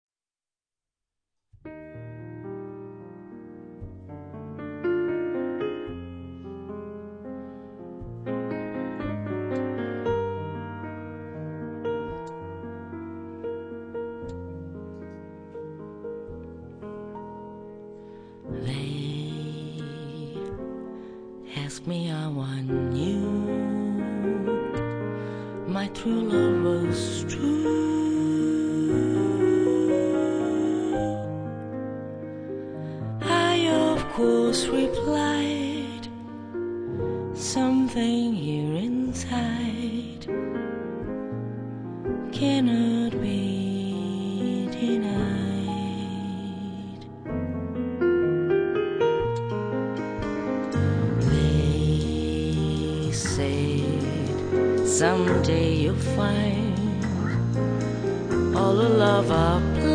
sax tenore
pianoforte
voce